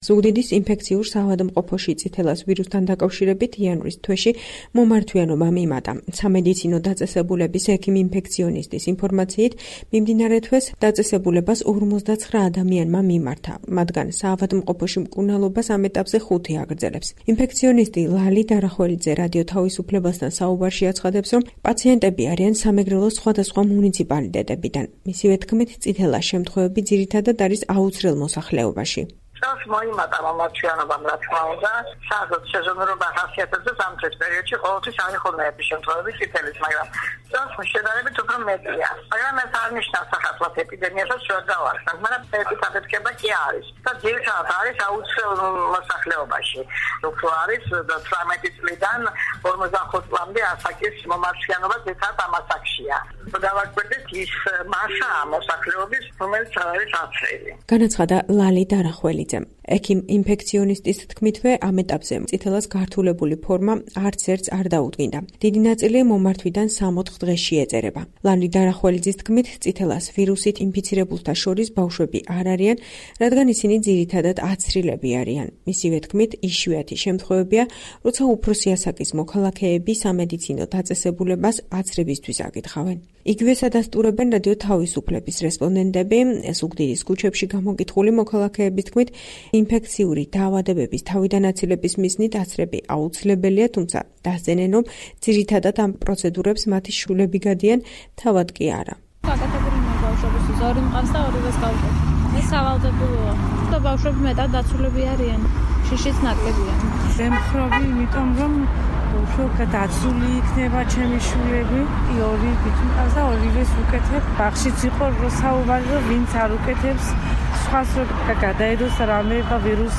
ამას ადასტურებენ რადიო თავისუფლების რესპონდენტებიც. ზუგდიდის ქუჩებში გამოკითხული მოქალაქეების თქმით, ინფექციური დაავადებების თავიდან აცილებისთვის აცრები აუცილებელია, თუმცა ამ პროცედურებს ძირითადად შვილები იტარებენ, მშობლები კი არა.